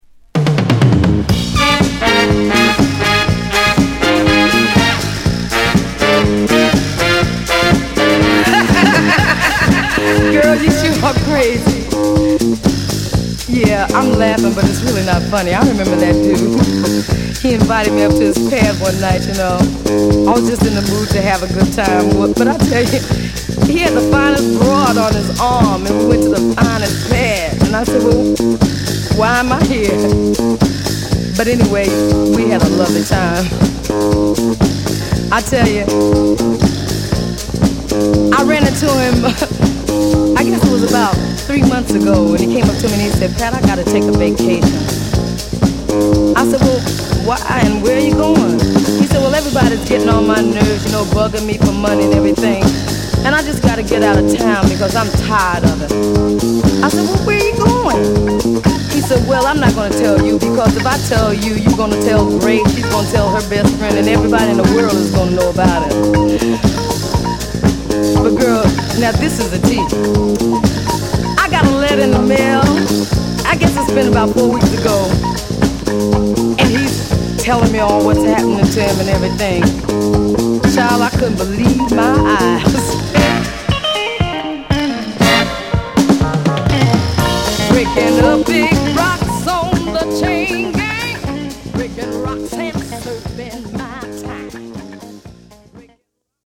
グイグイ引っ張るドラム＆ベースに陽気なホーンやシェイカーが絡むファンキーディスコチューン！